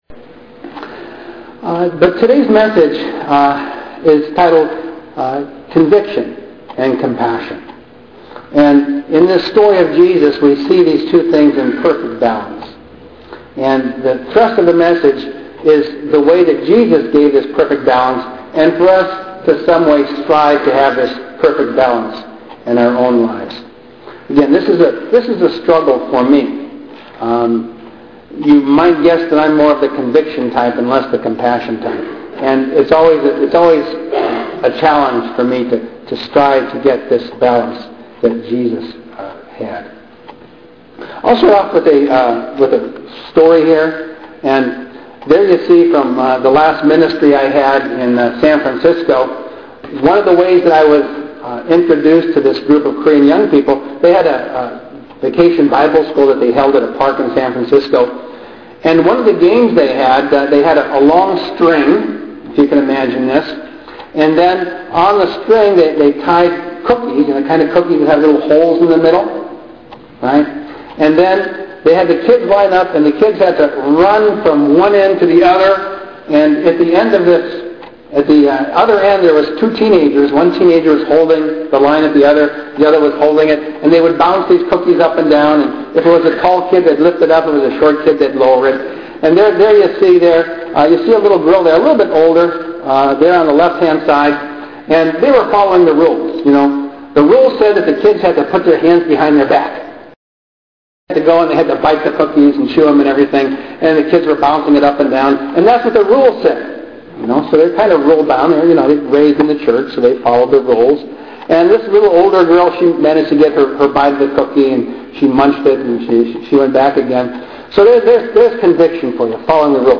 Sermon on Conviction and Compassion